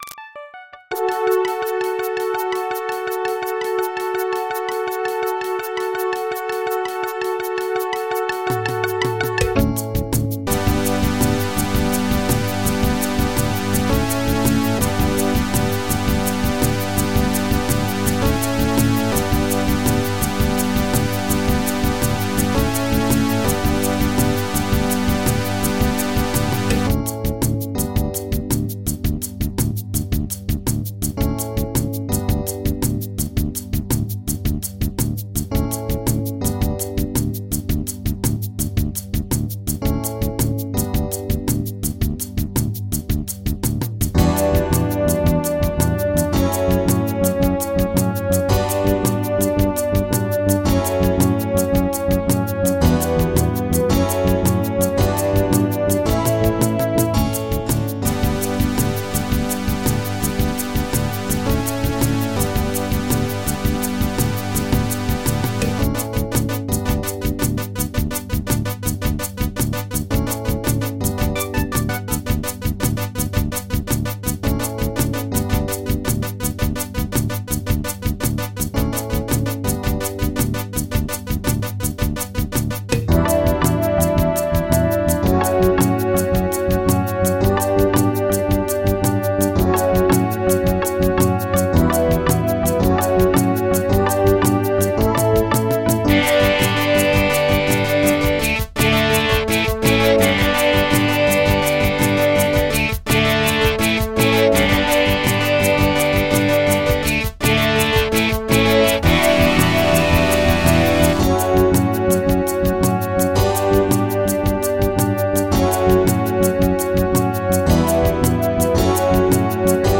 MIDI 51.31 KB MP3 (Converted)